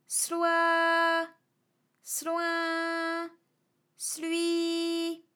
ALYS-DB-001-FRA - First, previously private, UTAU French vocal library of ALYS
sloi_sloin_slui.wav